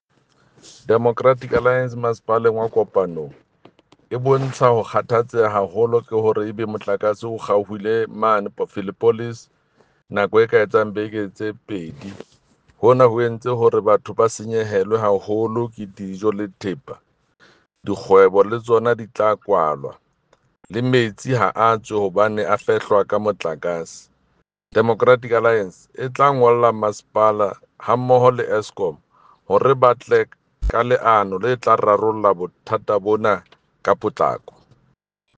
Sesotho soundbite by Jafta Mokoena MPL